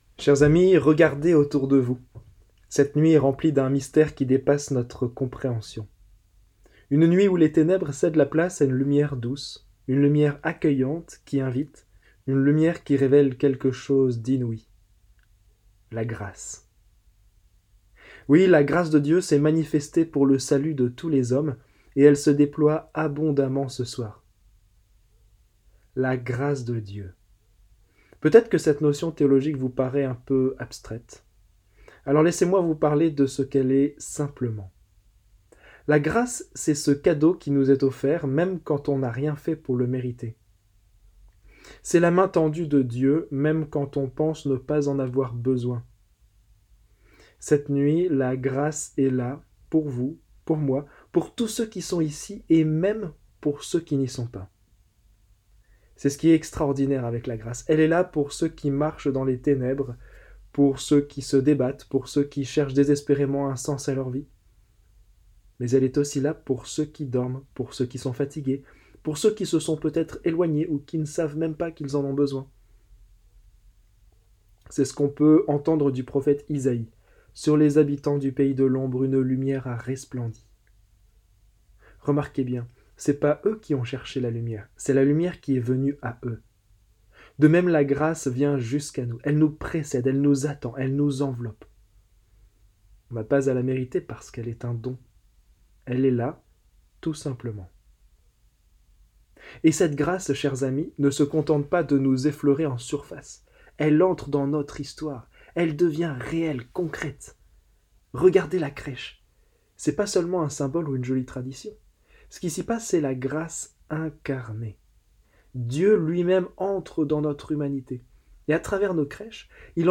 Homélies en 3 points